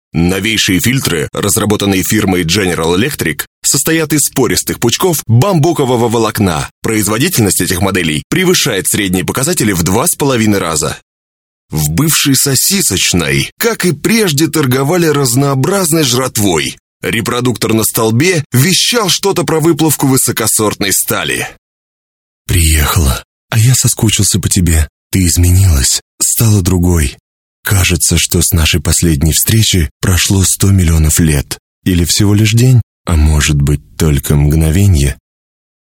Language - Russian, Timbre - a baritone. Russian voice-over.
Sprechprobe: Werbung (Muttersprache):